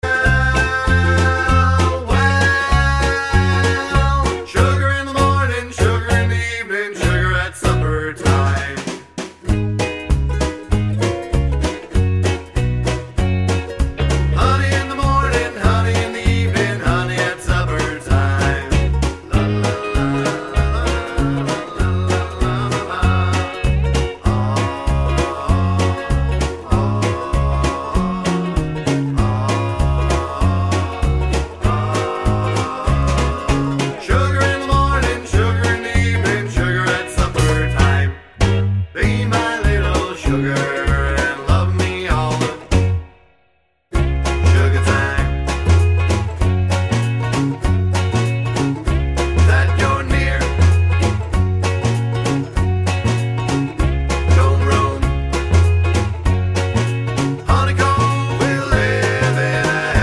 for female trio Oldies (Female) 2:31 Buy £1.50